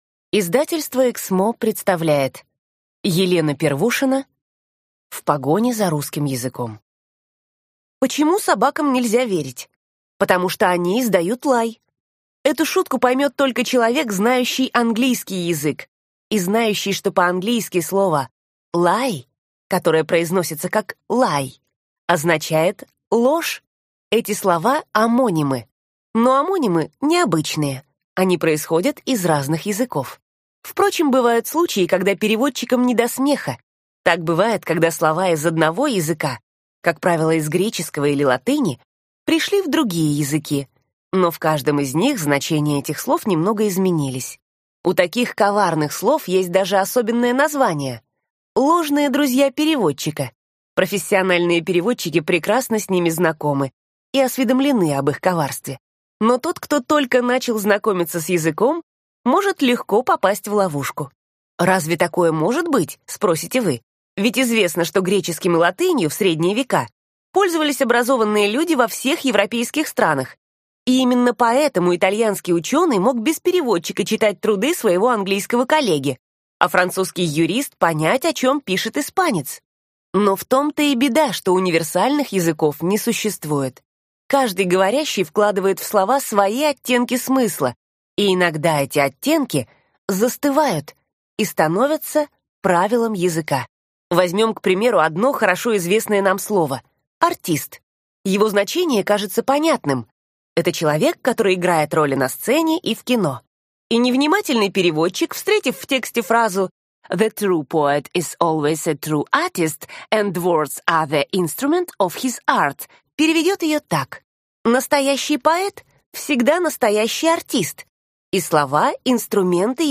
Аудиокнига В погоне за русским языком: заметки пользователя. Невероятные истории из жизни букв, слов и выражений | Библиотека аудиокниг